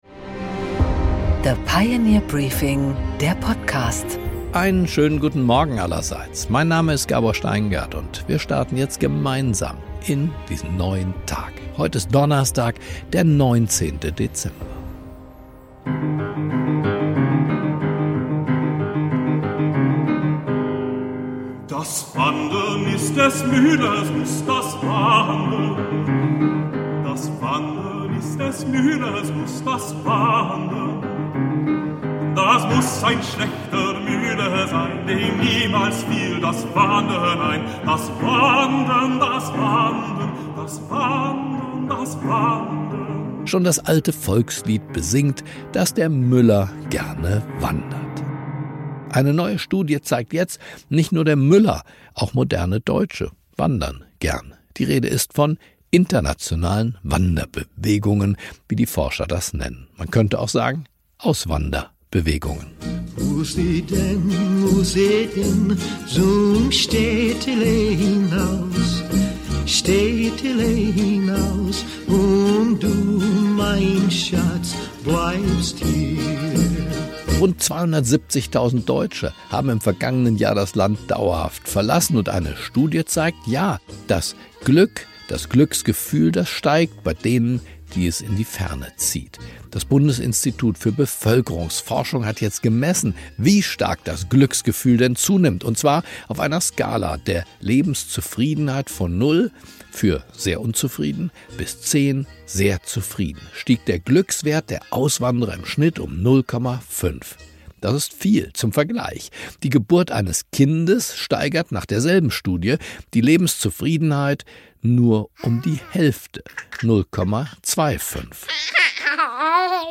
Gabor Steingart präsentiert das Pioneer Briefing
Im Interview: Matthias Miersch, SPD-Generalsekretär